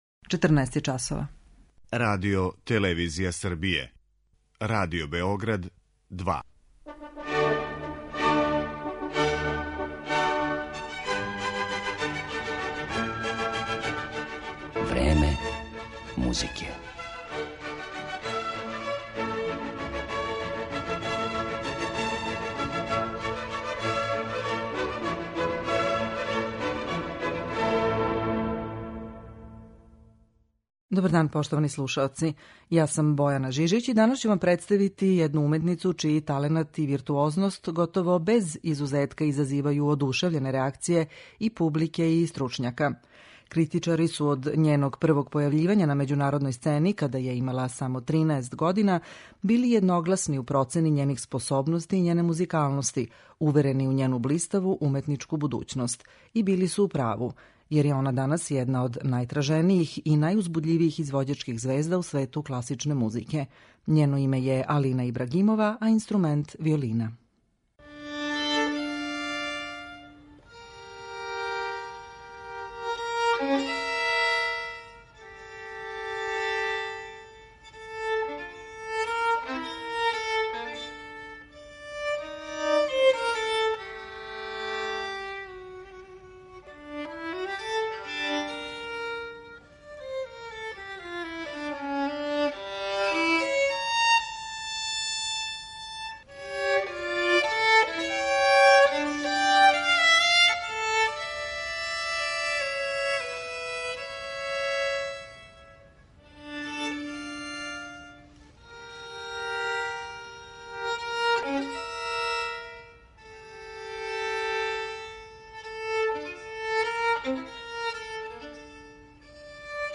Гошће емисије Време музике су чланице ансамбла Доне ди Белградо, који концертом у недељу у Коларчевој задужбини обележава значајан јубилеј - 15 година постојања.